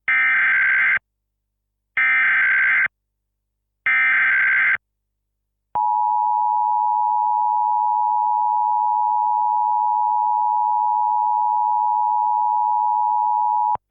Play, download and share emergency alert system button original sound button!!!!
emergency-alert-system-button.mp3